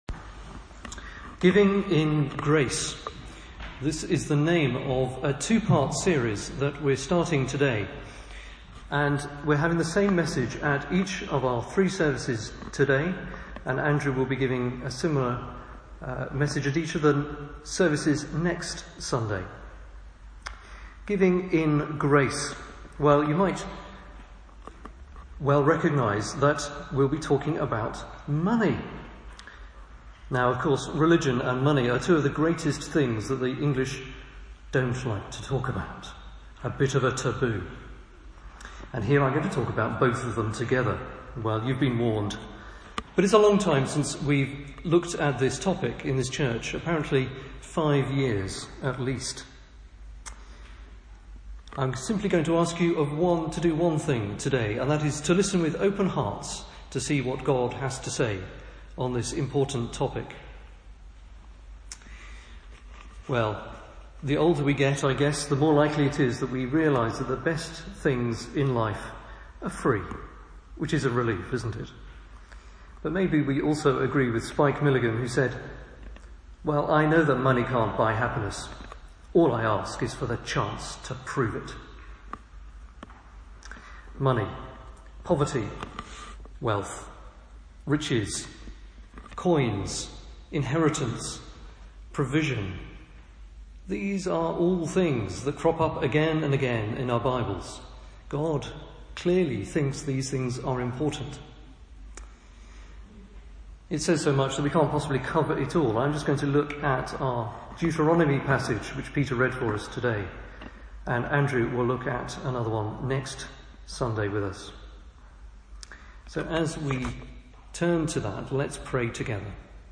The first of two sermons looking at the Generosity of God. This covers Deuteronomy 26 and the gifts of tithes.